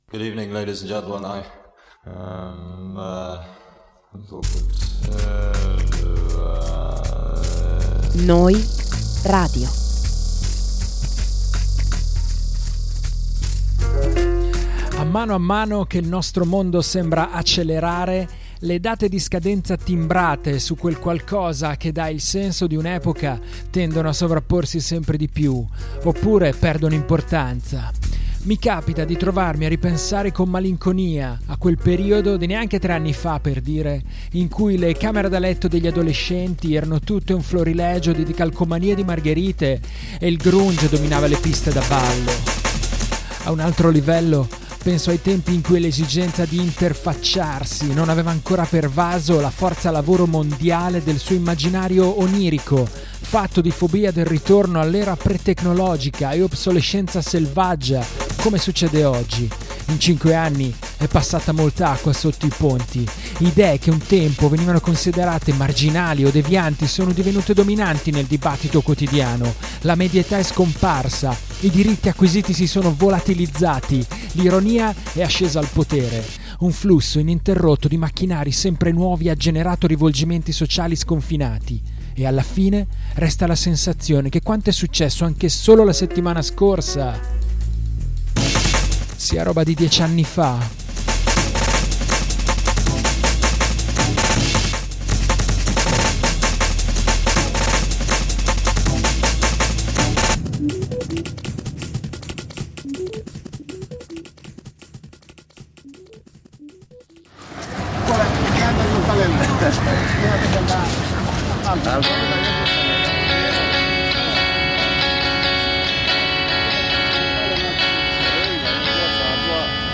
In effetti, ogni volta è una emozionante sorpresa scoprire che c’è qualcuno ancora qui, ad ascoltare questi sessanta minuti e questa bella selezione di novità indiepop e indie rock, senza dimenticare gli indispensabili brindisi, nella nuova …